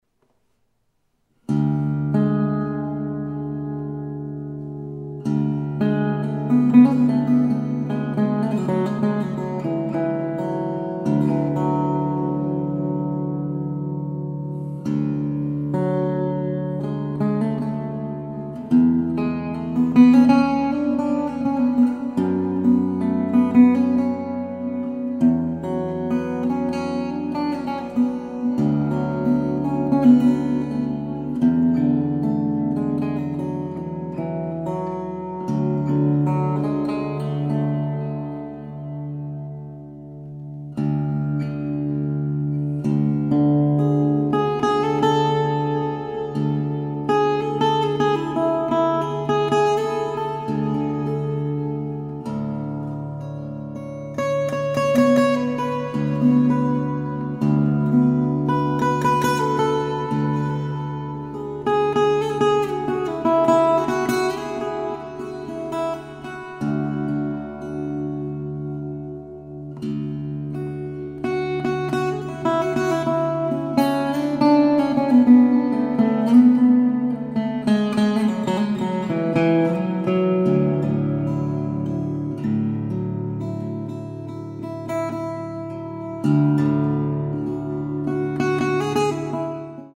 Composition & acoustic guitar